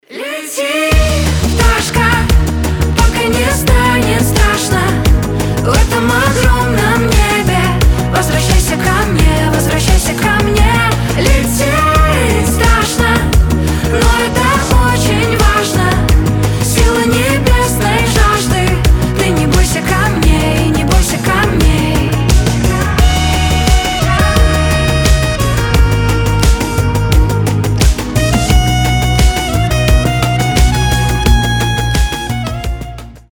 • Качество: 320, Stereo
приятные
Саксофон
красивый женский голос